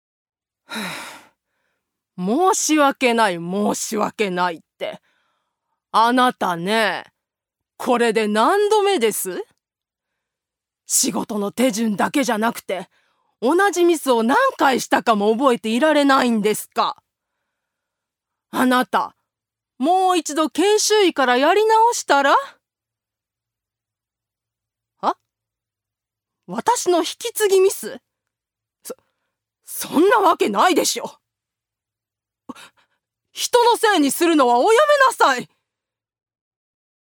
女性タレント
セリフ２